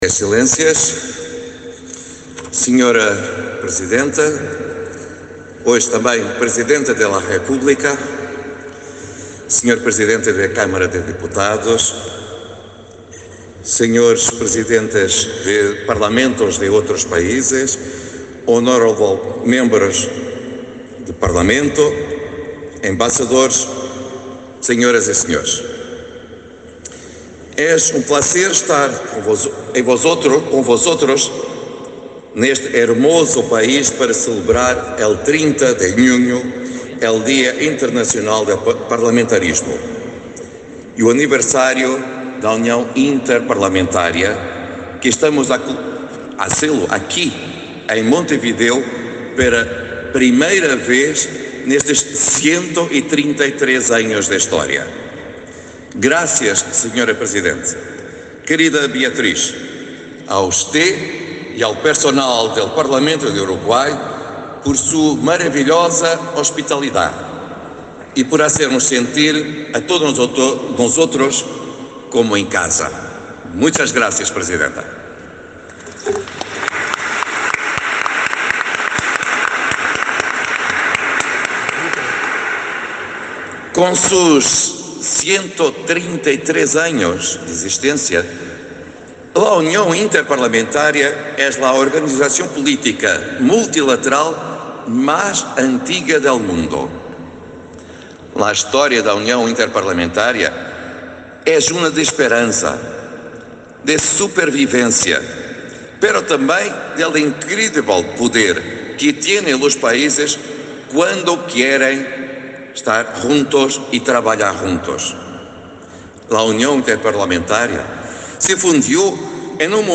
Palabras del presidente de la Unión Interparlamentaria, Duarte Pacheco
El presidente de la Unión Interparlamentaria, Duarte Pacheco, disertó este jueves 30 en el Palacio Legislativo, durante el acto de celebración del Día